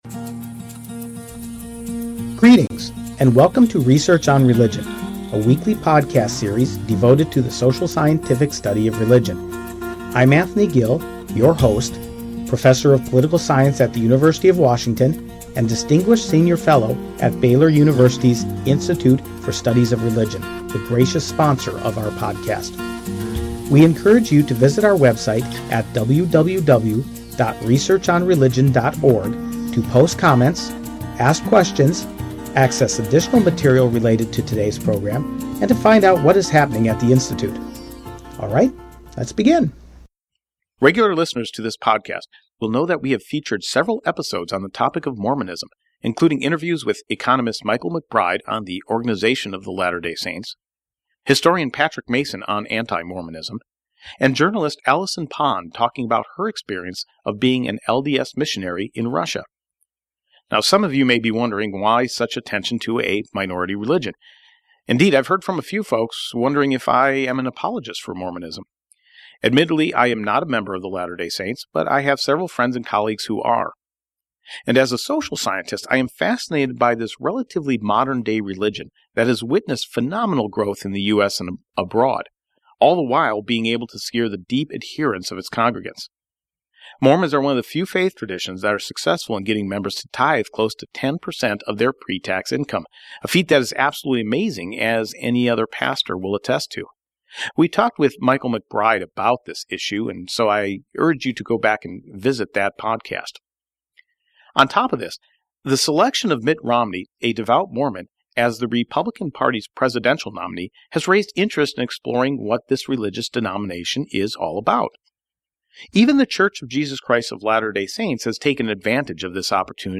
We hope you enjoy this interview and invite you to visit our archives for over 100 other great episodes on a wide variety of topics, all free to the public.